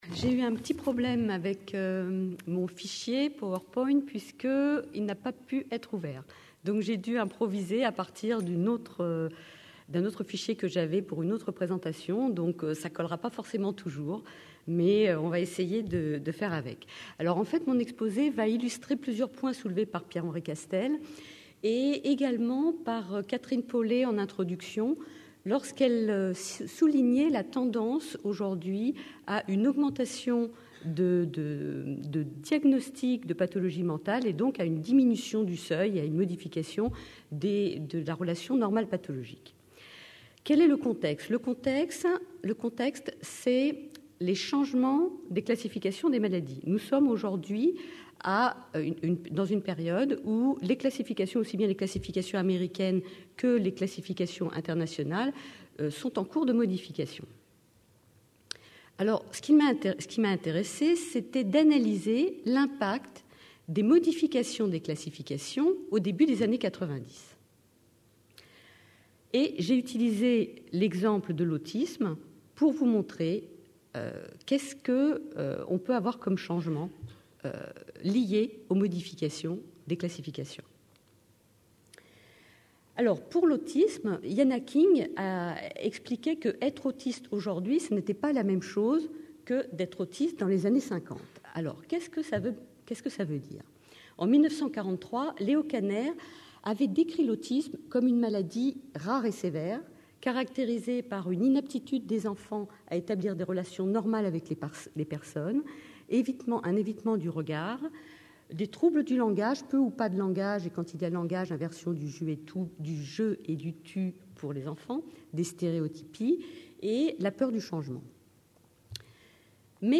CNIPsy 2010 Marseille : 7ème Congrès National des Internes en Psychiatrie (CNIPsy).